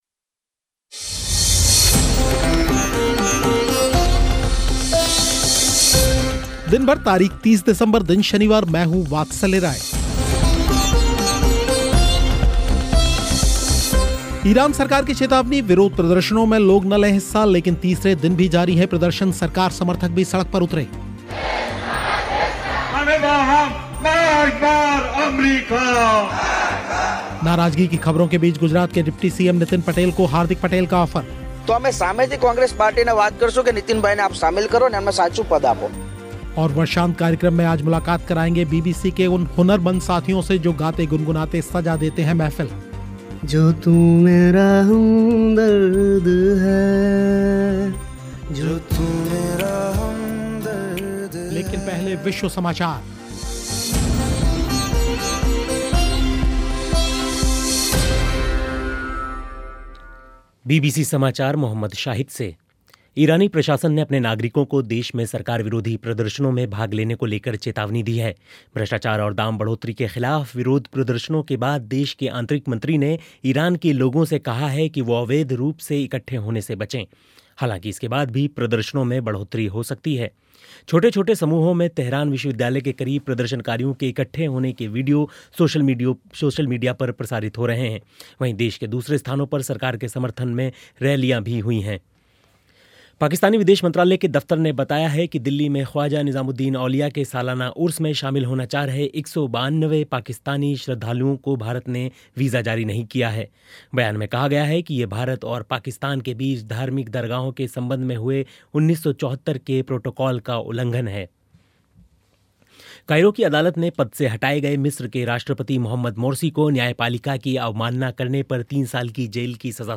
और वर्षांत कार्यक्रम में एक मुलाक़ात बीबीसी के उन हुनरमंद साथियों से जो गाते-गुनगुनाते सजा देते हैं महफिल